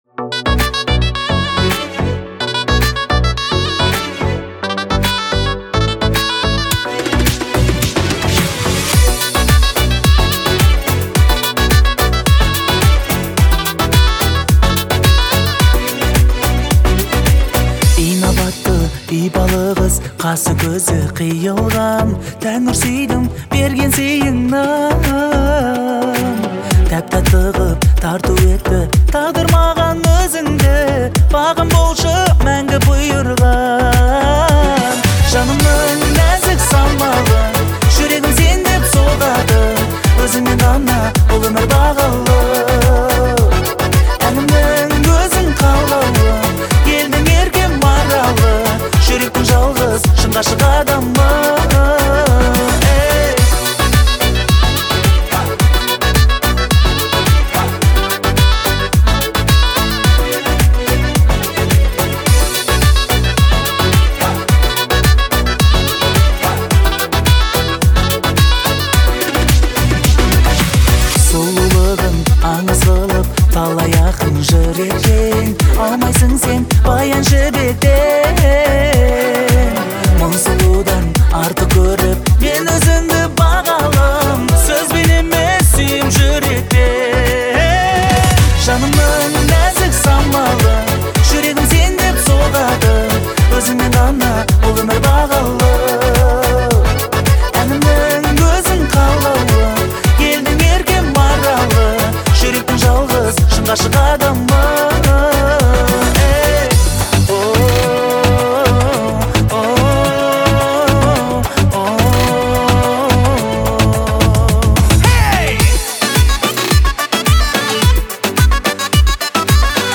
представляет собой романтичный трек в жанре казахского попа.